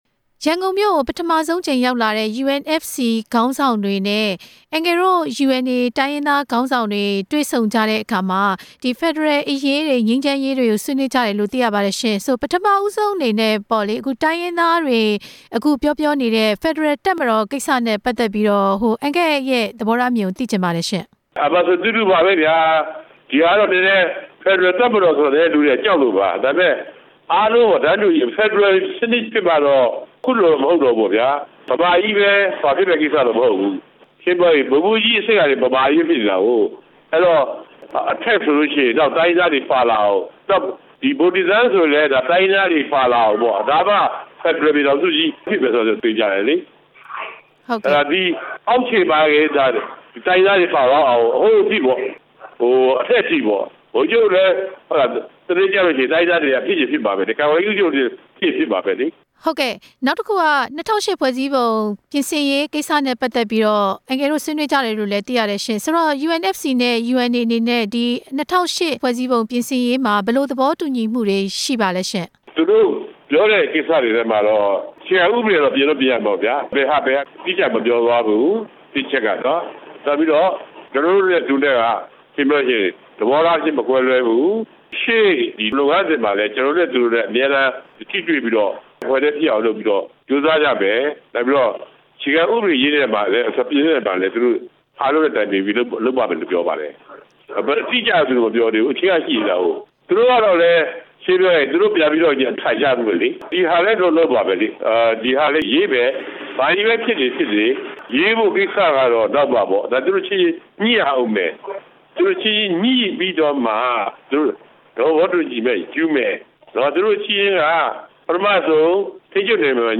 UNA ခေါင်းဆောင် ဦးခွန်ထွန်းဦးနဲ့ မေးမြန်းချက်